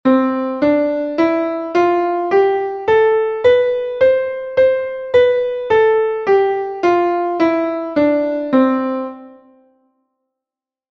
escaladoM.mp3